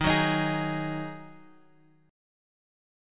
D#mbb5 chord {x 6 4 x 4 4} chord
Dsharp-Minor Double Flat 5th-Dsharp-x,6,4,x,4,4.m4a